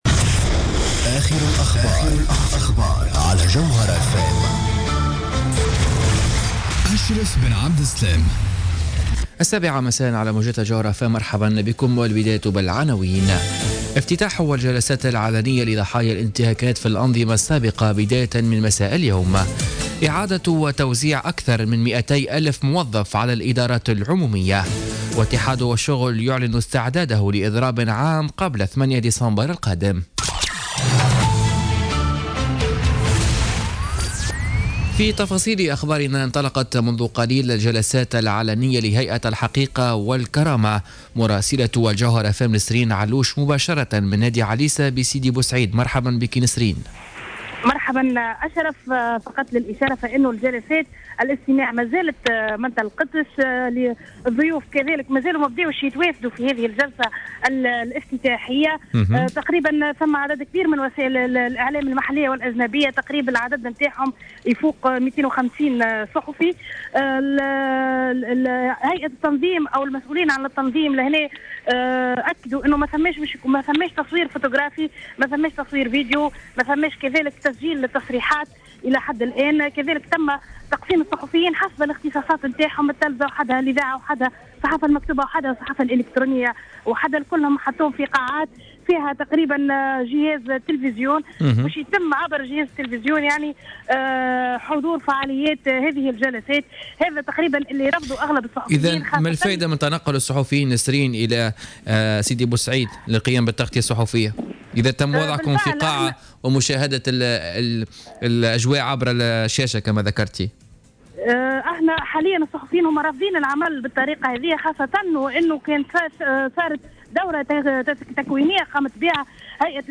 Journal Info 19h00 du Jeudi 17 Novembre 2016